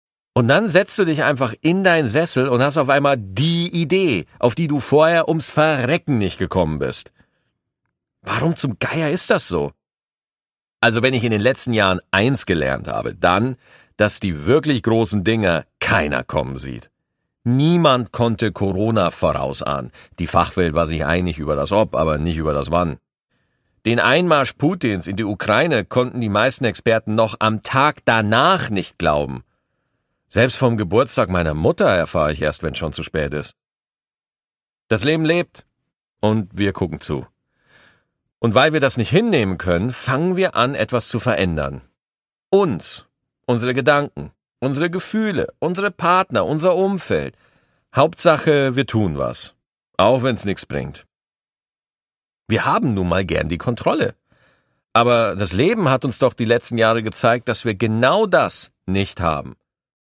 Produkttyp: Hörbuch-Download
Gelesen von: Maxi Gstettenbauer